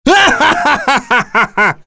Kain-Vox_Skill6_b.wav